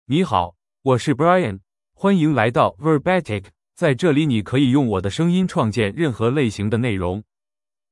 Brian — Male Chinese (Mandarin, Simplified) AI Voice | TTS, Voice Cloning & Video | Verbatik AI
Brian is a male AI voice for Chinese (Mandarin, Simplified).
Voice sample
Male
Brian delivers clear pronunciation with authentic Mandarin, Simplified Chinese intonation, making your content sound professionally produced.